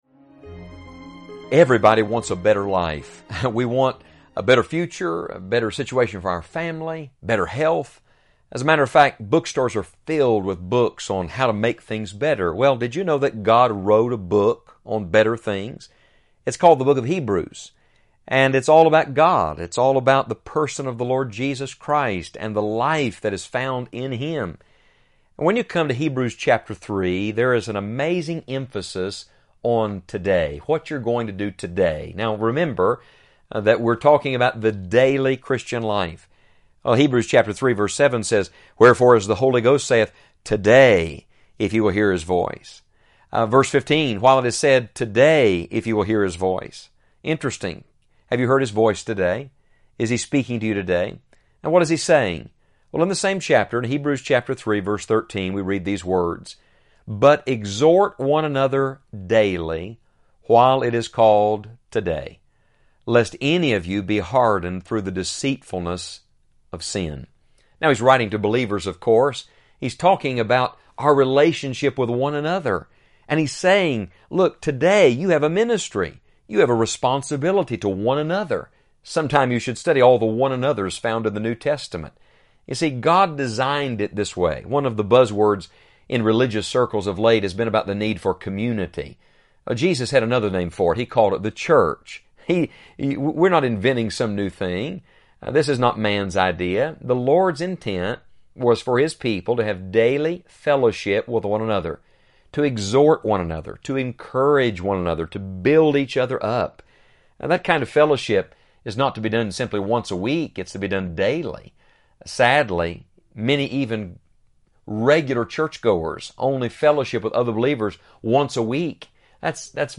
10-minute devotional Bible study broadcast